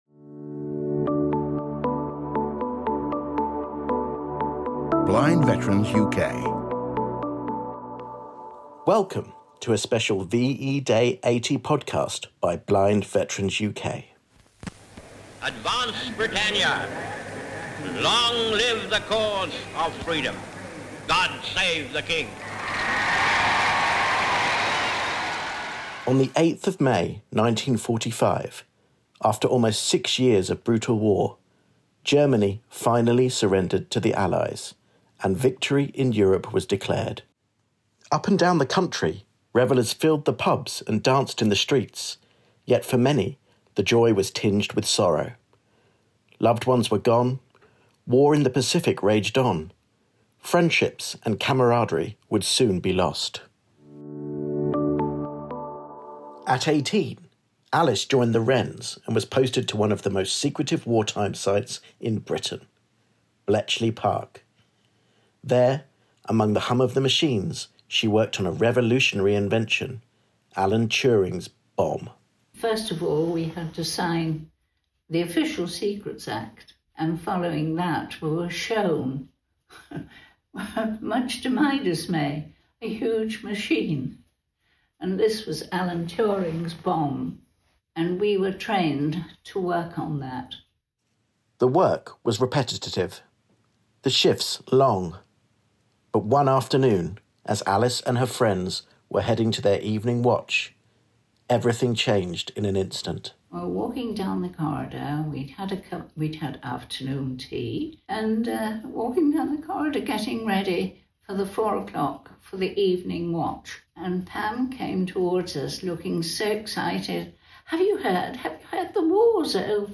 The wartime heroes we support today share their powerful stories of VE Day, 80 years on.